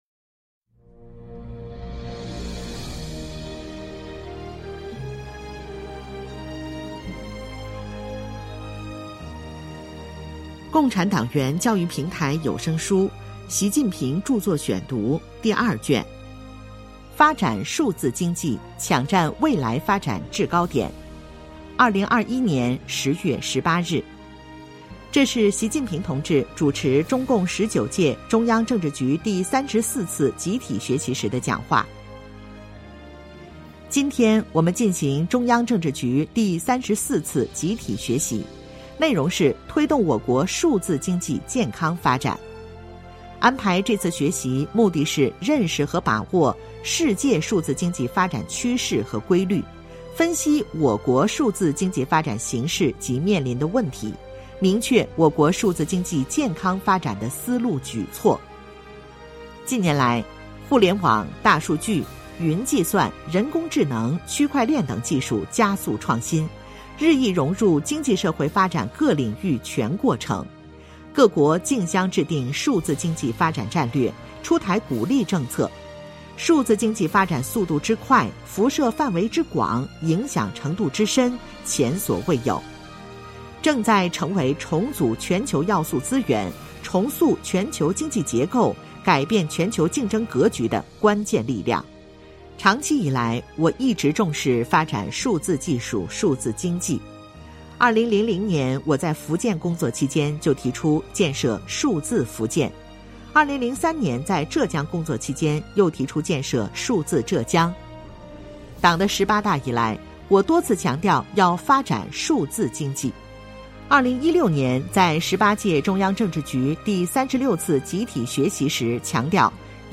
聊城机关党建 - 《习近平著作选读》第二卷 - 主题教育有声书 《习近平著作选读》第二卷（76）